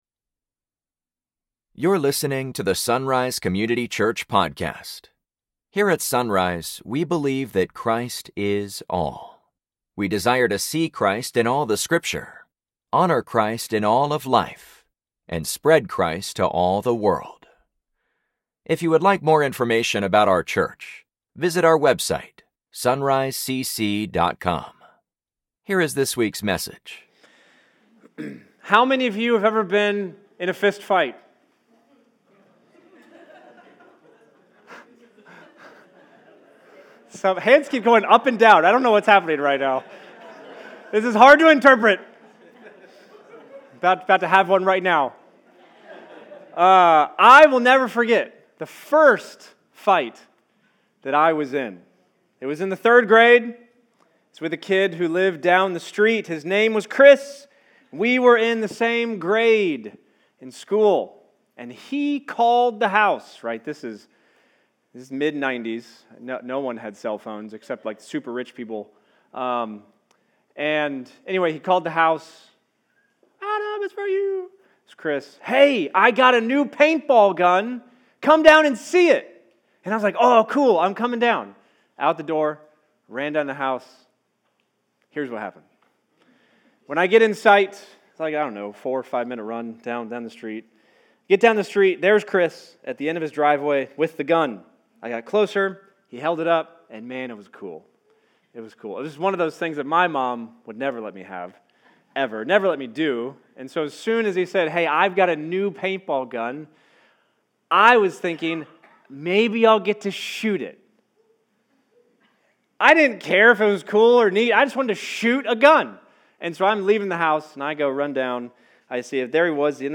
Sunday Mornings | SonRise Community Church